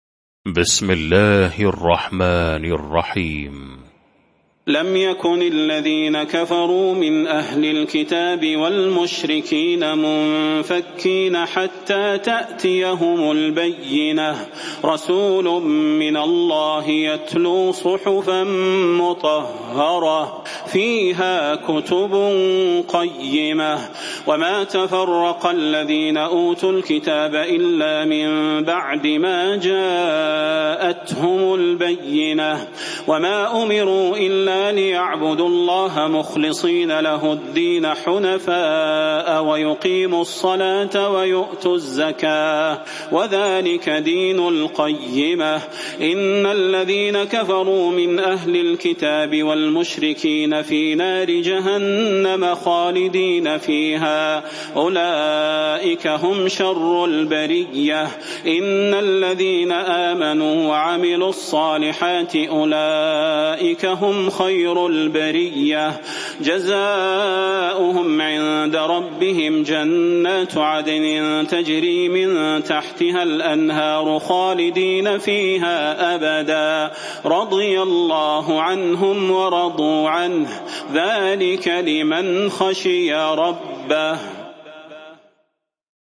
المكان: المسجد النبوي الشيخ: فضيلة الشيخ د. صلاح بن محمد البدير فضيلة الشيخ د. صلاح بن محمد البدير البينة